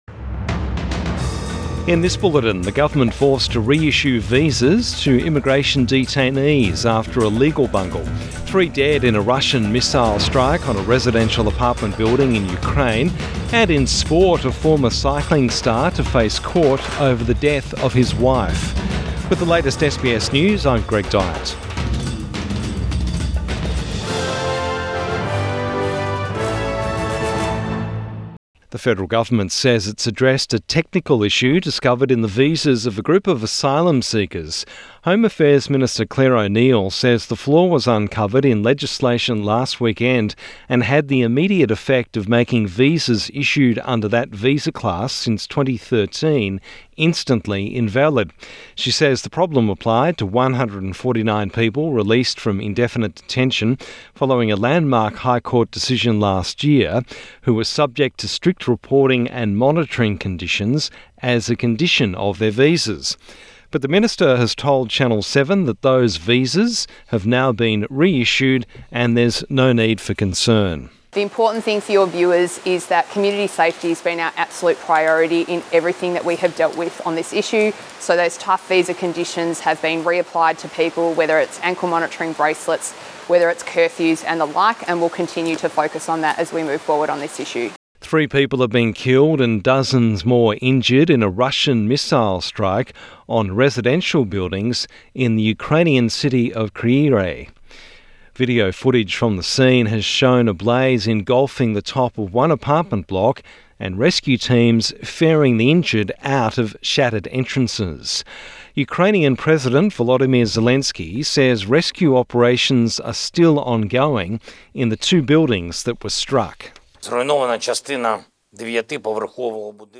Midday News Bulletin 13 March 2024